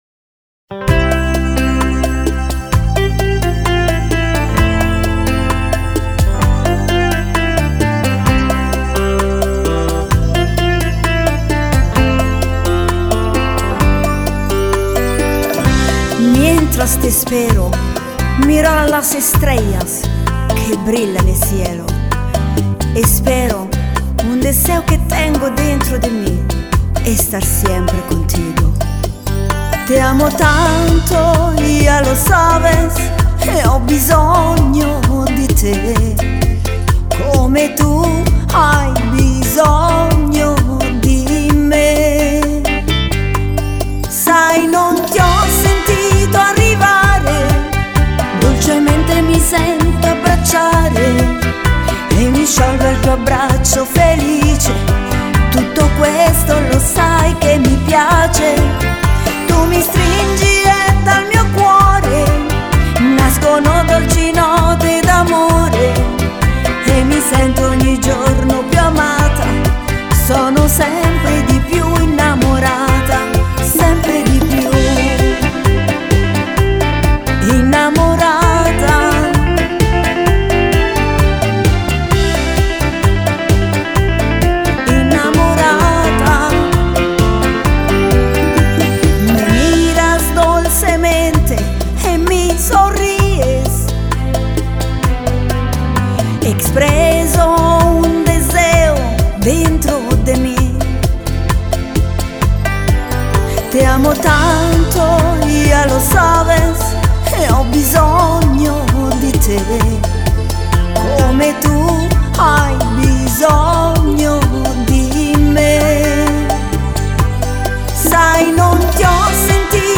Bachata